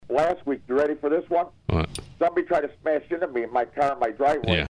Crash
Category: Radio   Right: Personal